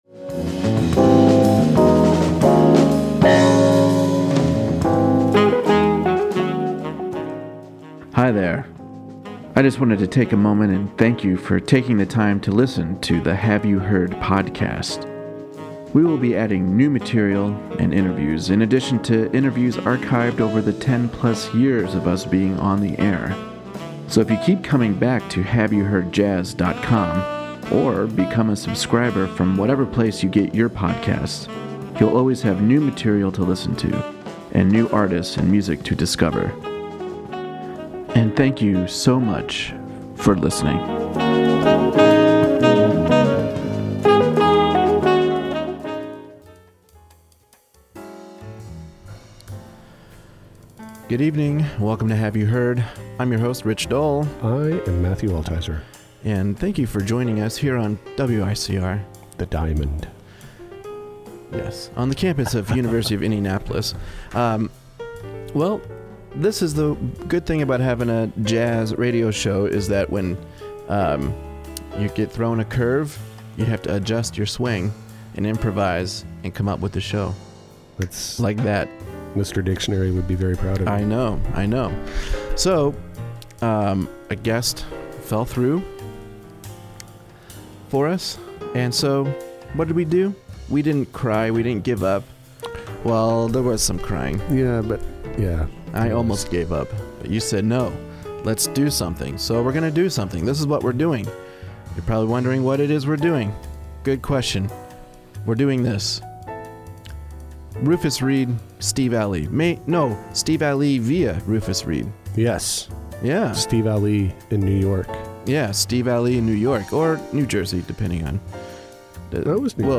He always sounds so effortless whether he is playing a written line, comping, or soloing.